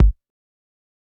Kicks
BattleCatPerfectKick.wav